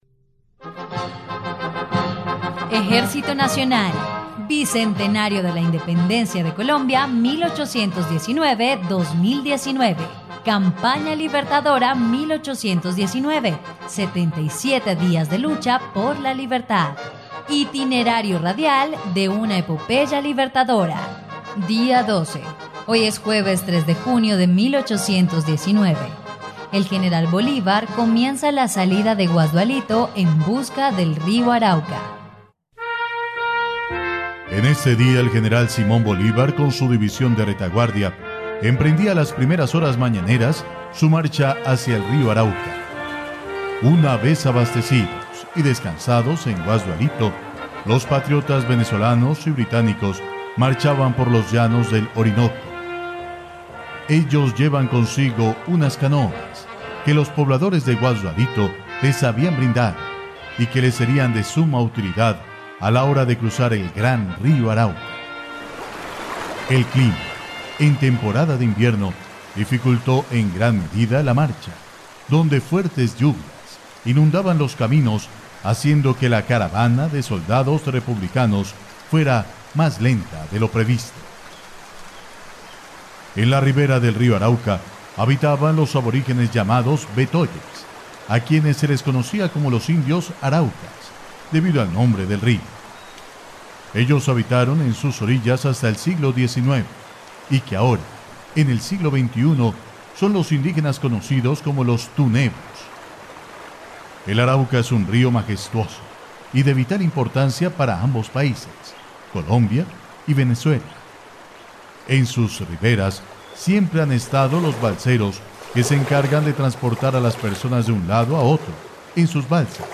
dia_12_radionovela_campana_libertadora.mp3